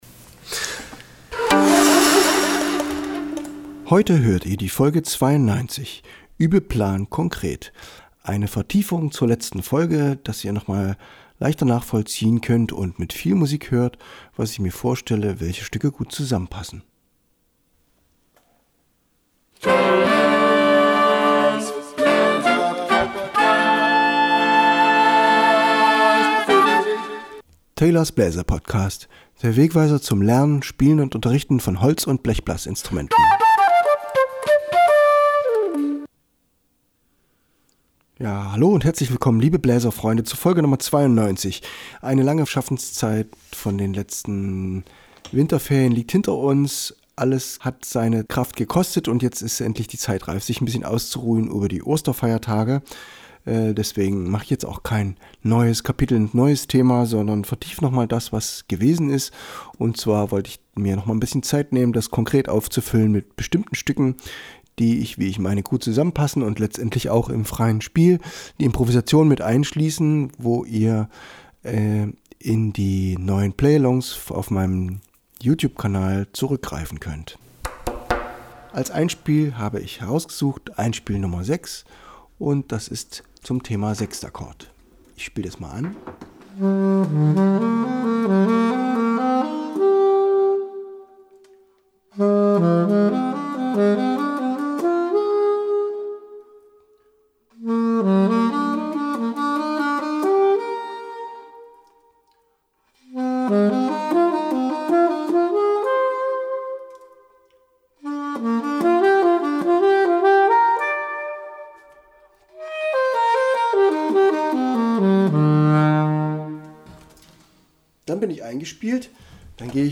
Letzte Episode 92: Übeplan konkret 29. März 2023 Nächste Episode download Beschreibung Teilen Abonnieren Hörinspiration, Übeideen und die Struktur, die ihr aus Folge 91 kennt, mit musikalischen Beispielen gefüllt. Impro Tipps für den Tango "Police Stop Accident", den ihr auch als Bandversion hört.